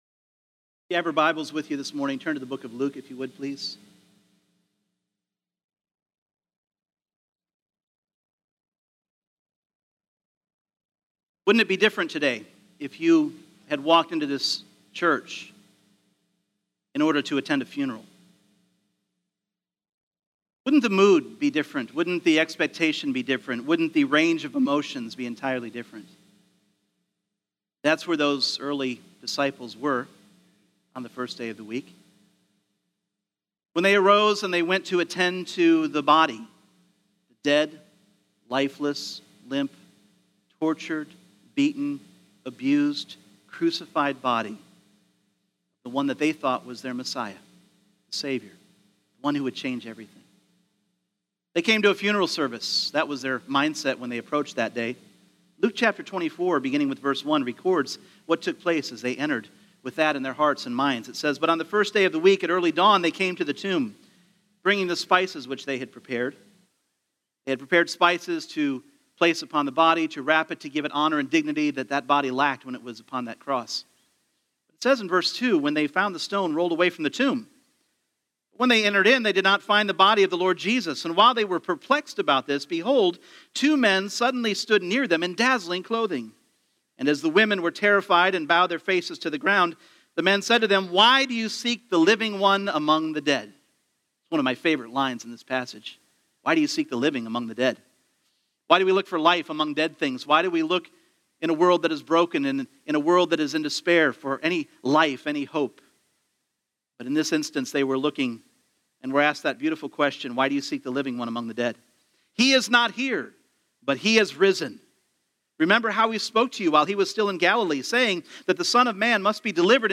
Individual Messages Service Type: Sunday Morning The Resurrection of Jesus Christ changed everything!